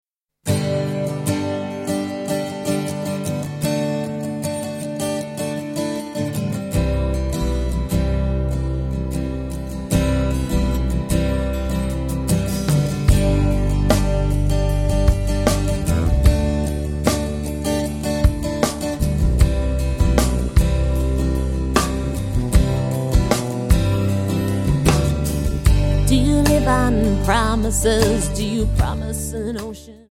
Alternative,Blues,Folk